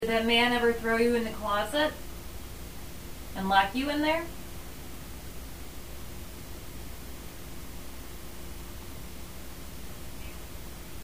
Right Bedroom – 9:33 pm
There is a noise in response to a question